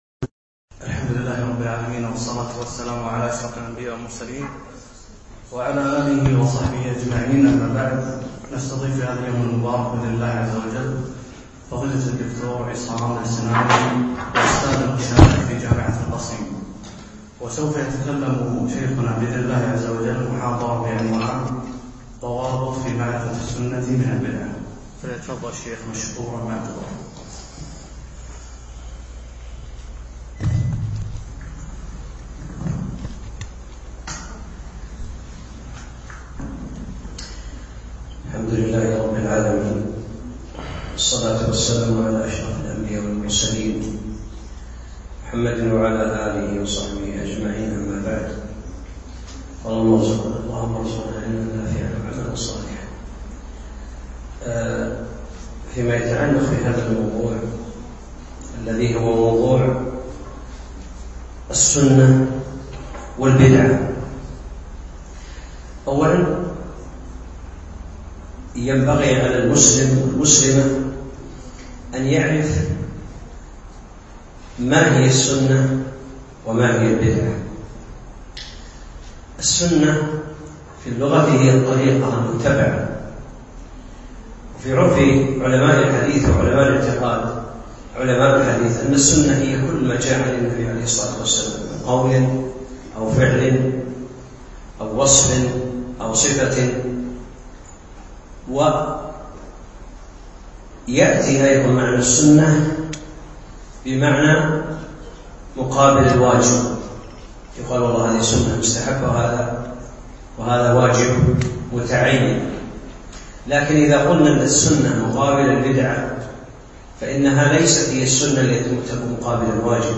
أقيمت المحاضرة في مركز الفردوس نساء مسائي